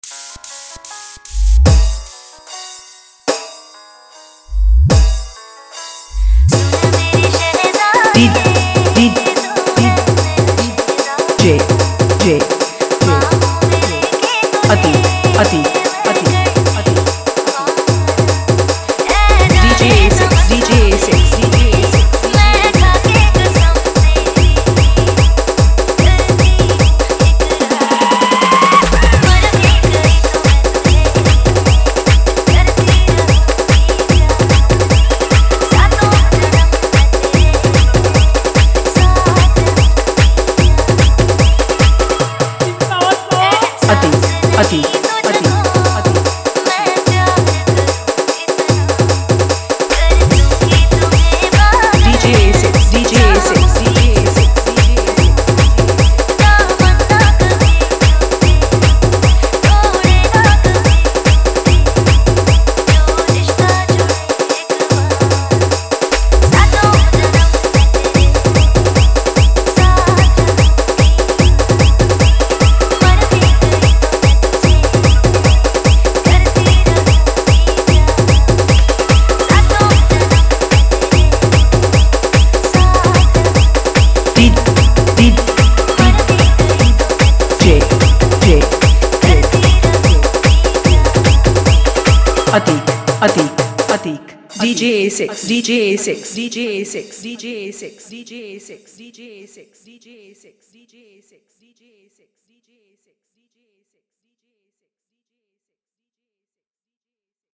Dj Remix Gujarati